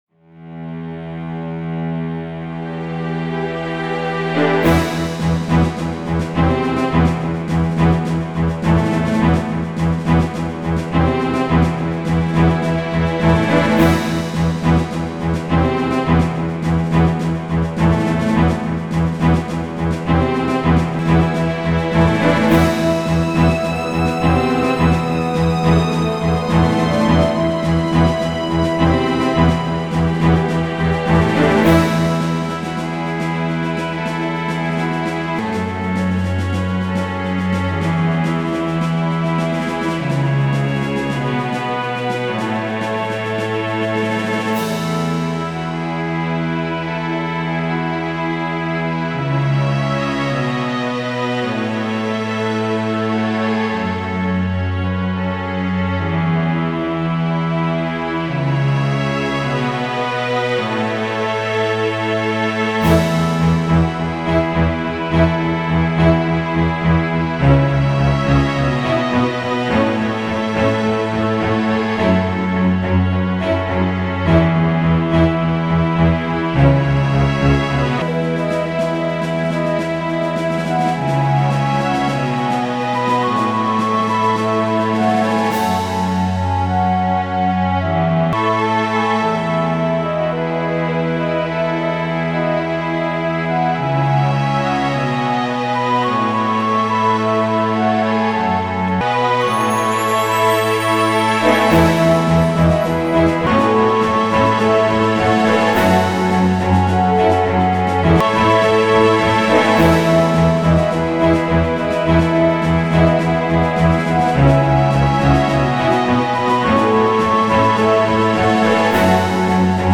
Genre: Enigmatic.